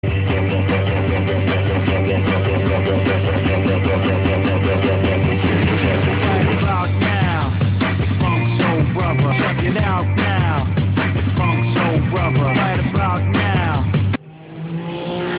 Есть аудио-отрывок очень популярной в середине 2000-х англоязычной песни, пожалуйста, помогите определить, что за группа и как называется этот трек.
English band song.mp3